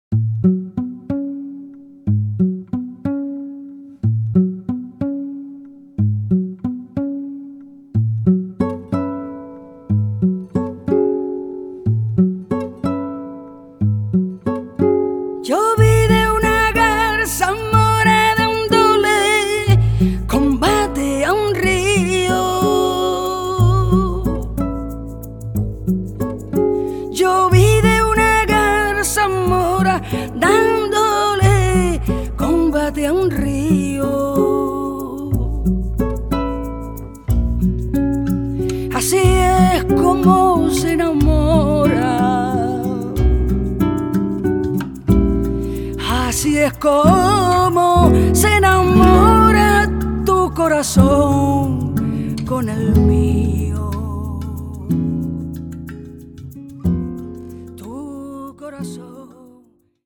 flamenco-gitarre, laúd, perc.
kontrabass, e-bass
percussion
mundharmonika